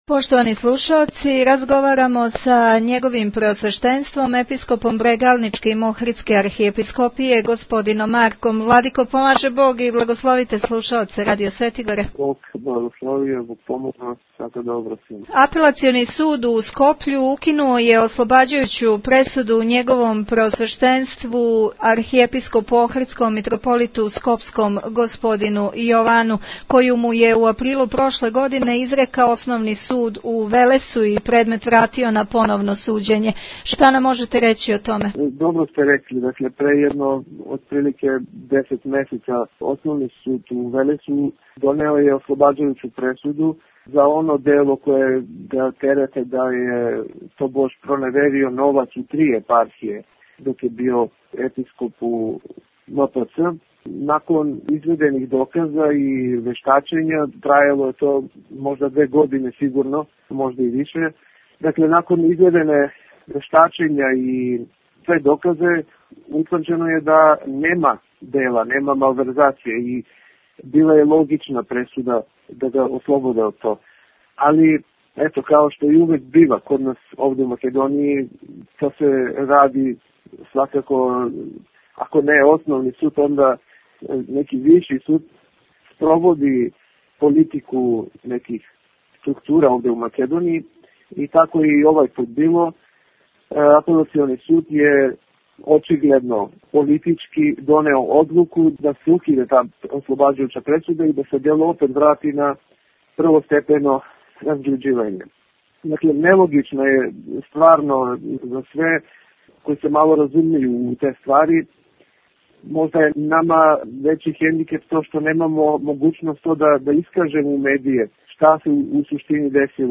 О томе са Преосвећеним Епископом Брегалничким Охридске Архиепископије Г. Марком разговарала